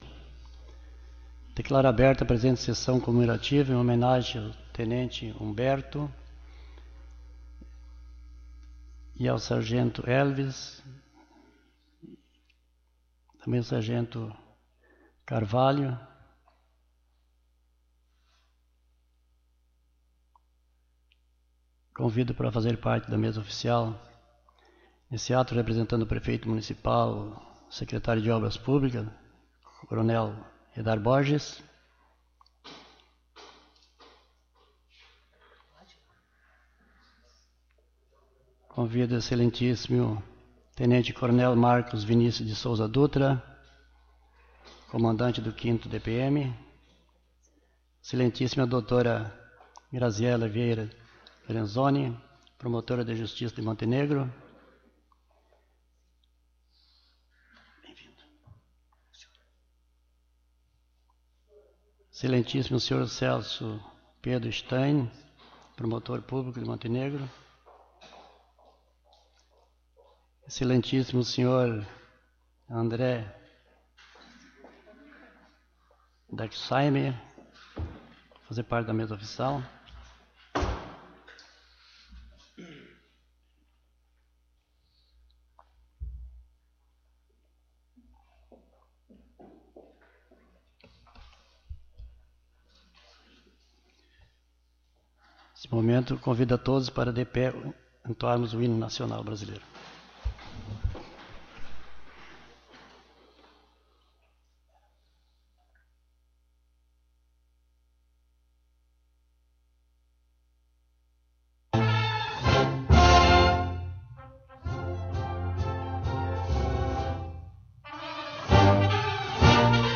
Sessão Comemorativa PMs